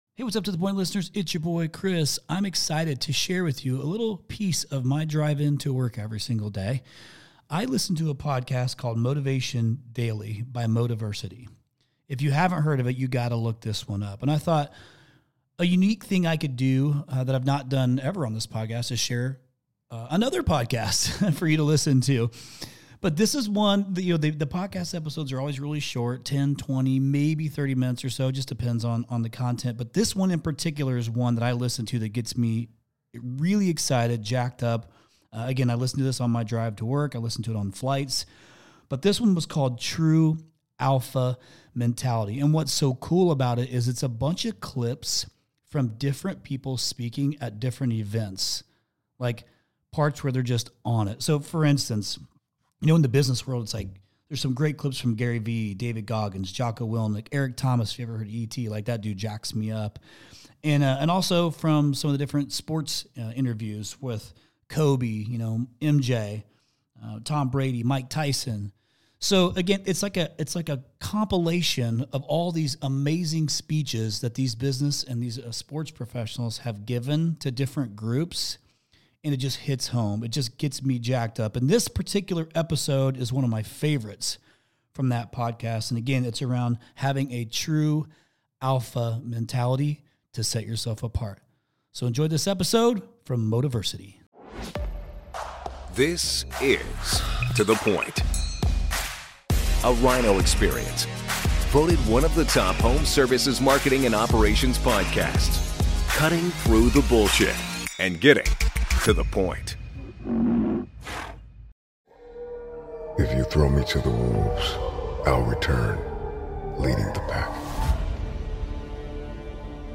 featuring electrifying clips from top business and sports icons like Gary Vee, David Goggins, Jocko Willink, Eric Thomas, Kobe Bryant, Michael Jordan, Tom Brady, and Mike Tyson.